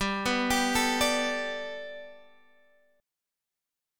Gadd9 Chord